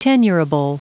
Prononciation du mot tenurable en anglais (fichier audio)
Prononciation du mot : tenurable